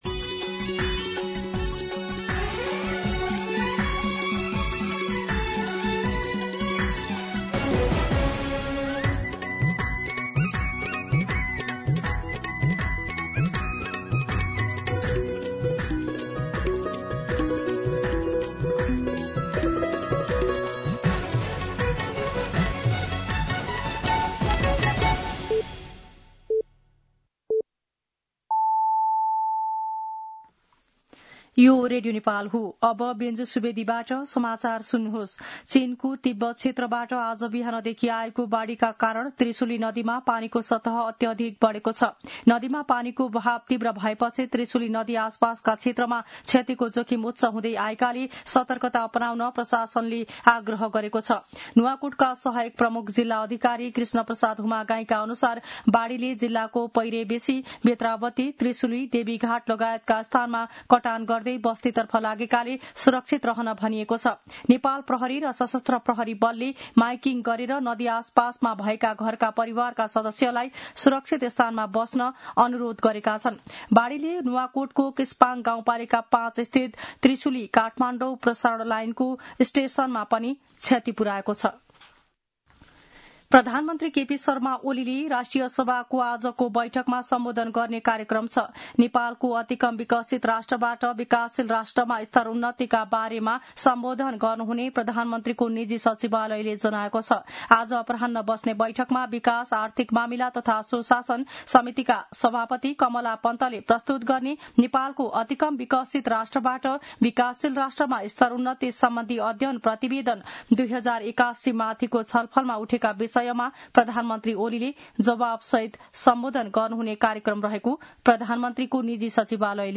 मध्यान्ह १२ बजेको नेपाली समाचार : १४ साउन , २०८२